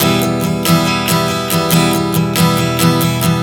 Strum 140 Em 03.wav